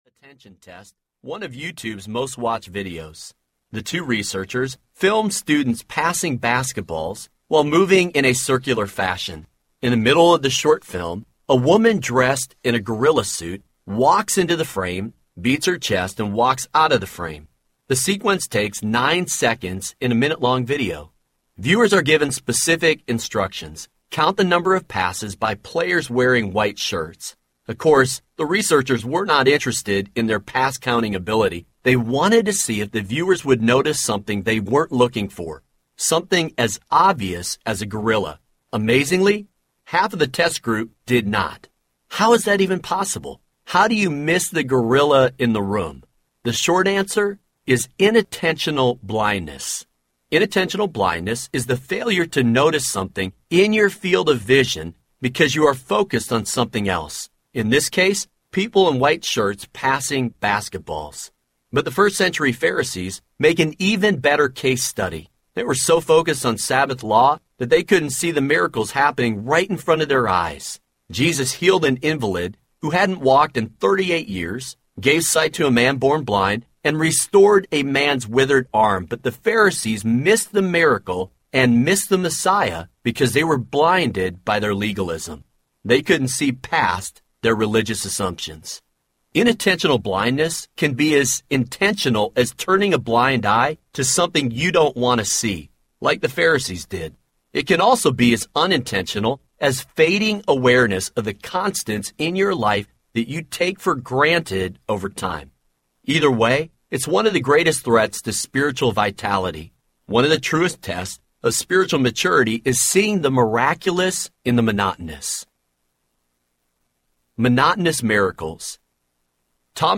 The Grave Robber Audiobook